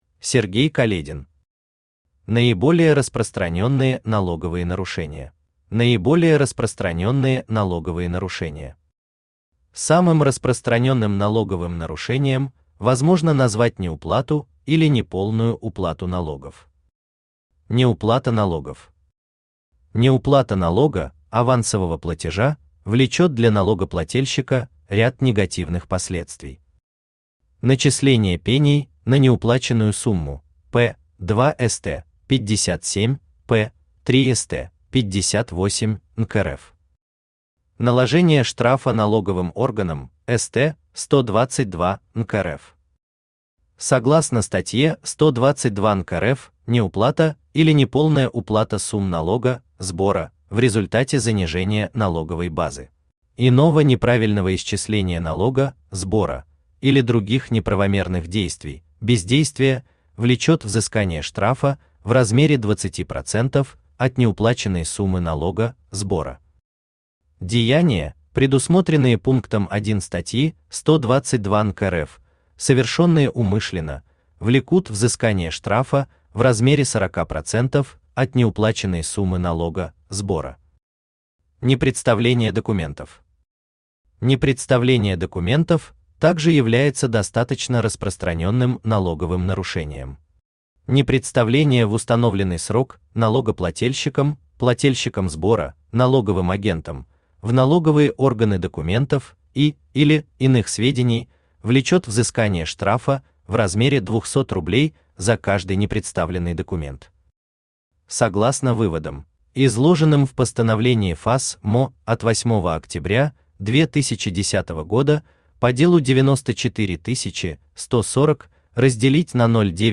Аудиокнига Наиболее распространенные налоговые нарушения | Библиотека аудиокниг
Aудиокнига Наиболее распространенные налоговые нарушения Автор Сергей Каледин Читает аудиокнигу Авточтец ЛитРес.